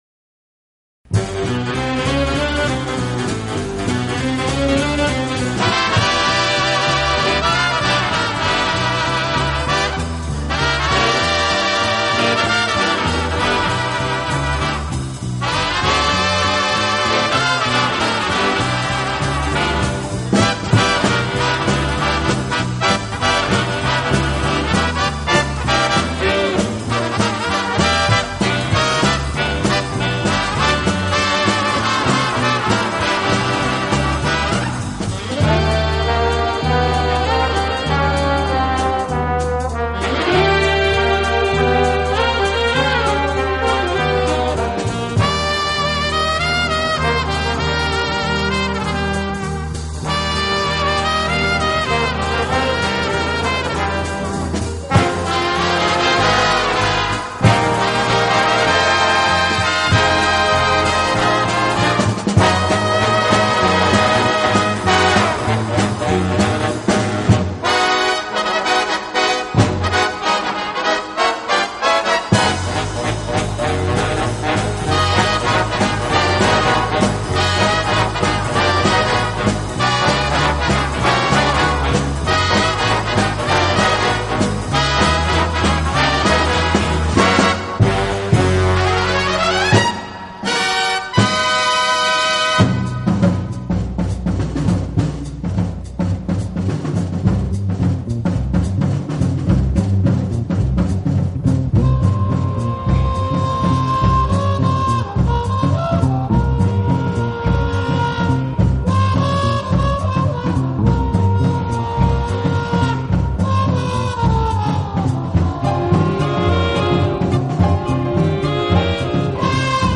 【轻音乐】
轻快、柔和、优美，带有浓郁的爵士风味。